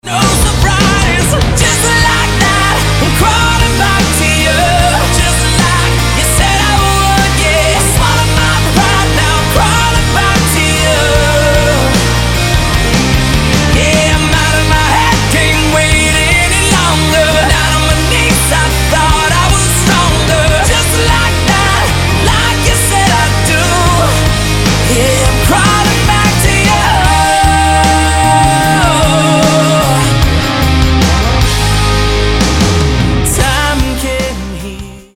americkej rockovej kapely